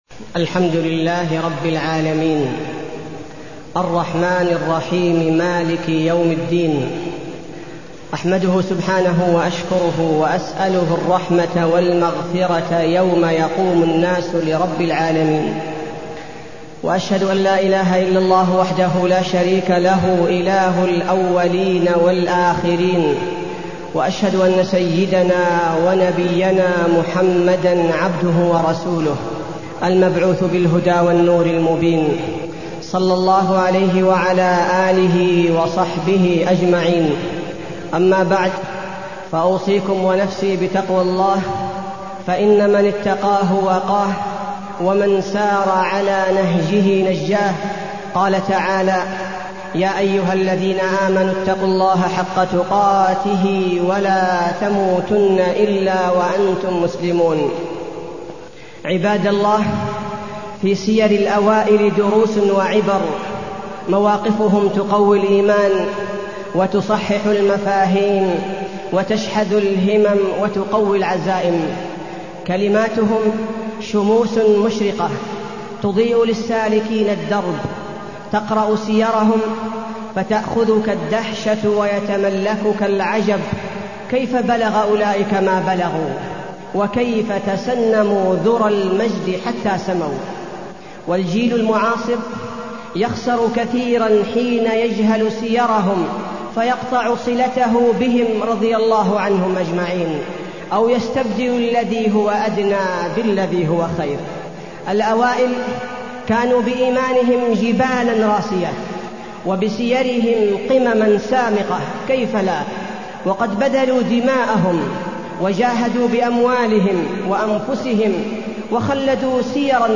تاريخ النشر ١٠ جمادى الآخرة ١٤٢١ هـ المكان: المسجد النبوي الشيخ: فضيلة الشيخ عبدالباري الثبيتي فضيلة الشيخ عبدالباري الثبيتي قصة الثلاثة الذين خلفوا